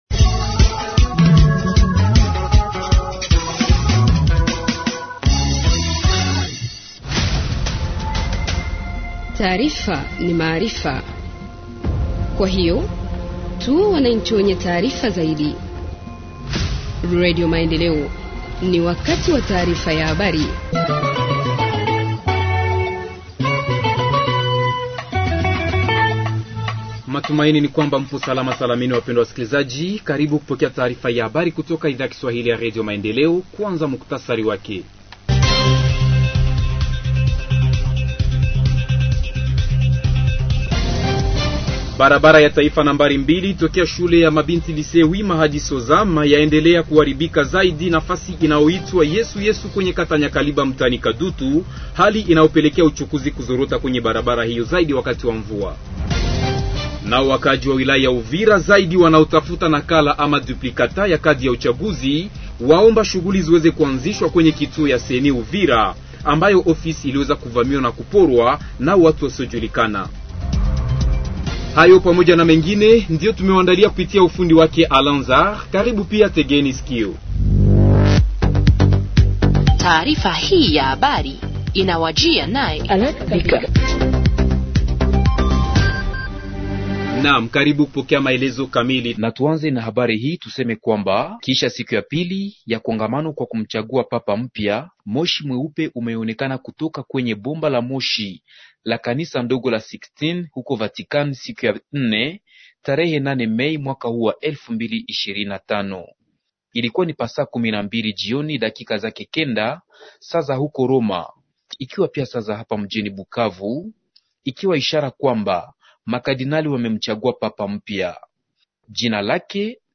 Journal en Kiswahili du 09 Mai 2025 – Radio Maendeleo